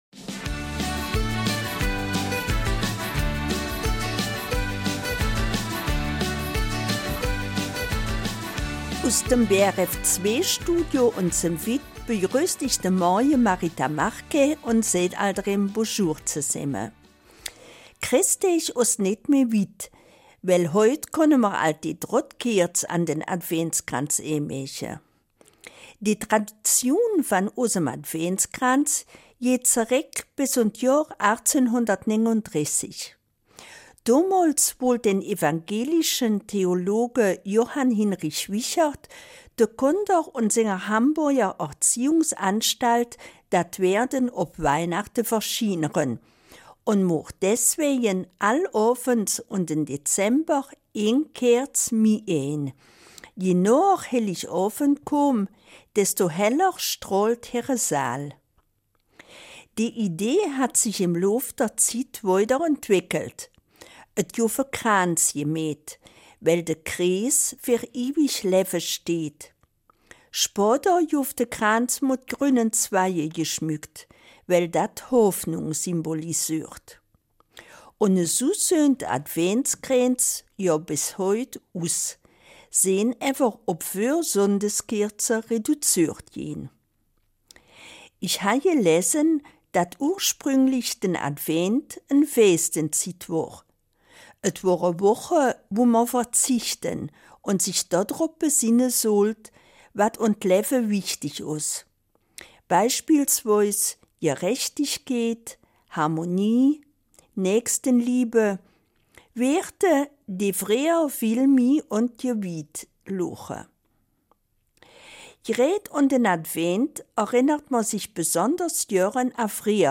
Eifeler Mundartsendung: Vorweihnachtszeit